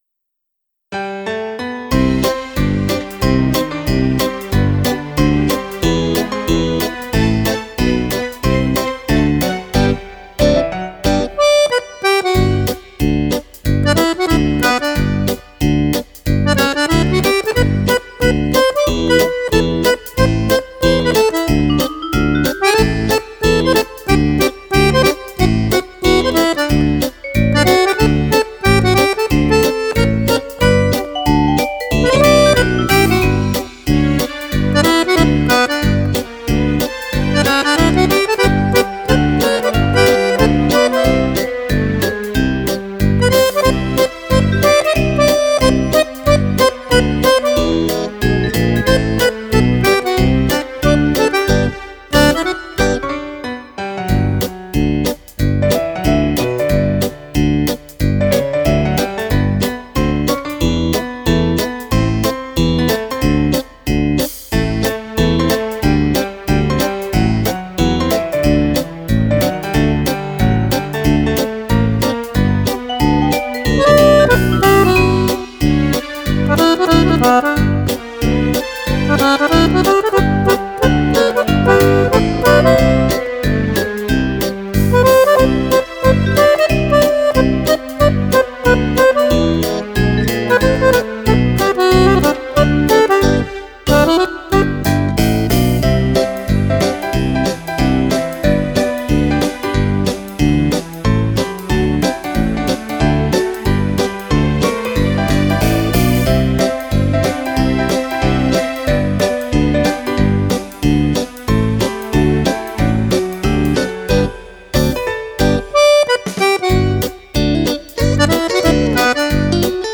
gran fox-trot per Fisarmonica